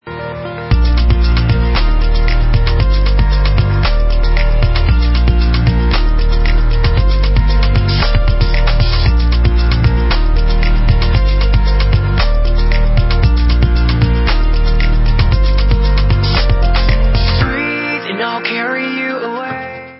disco, evropský elektropop a osmdesátkový synthpop
Čerstvý, zasněný pop pro každého!